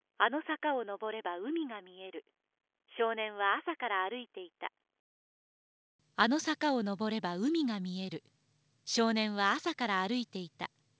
初めに「従来の電話」での声が流れ、次に、広帯域音声での表現力豊かな声が流れます。